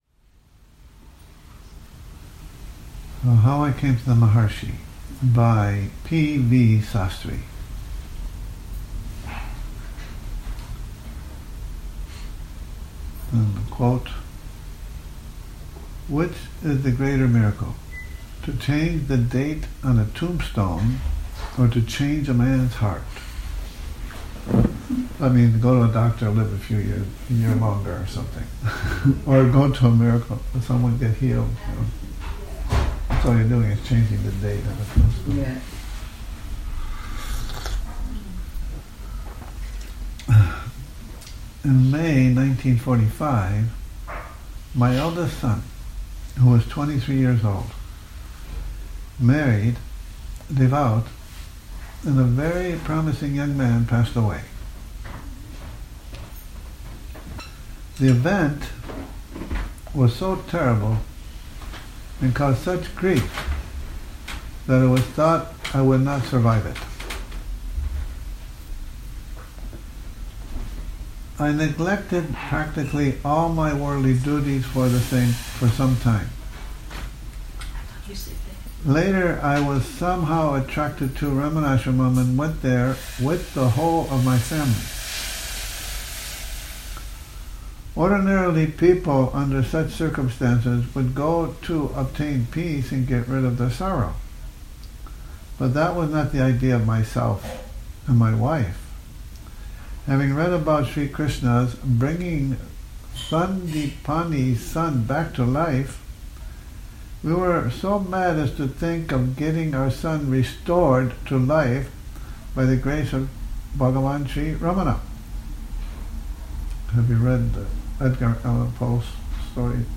Morning Reading, 10 Oct 2019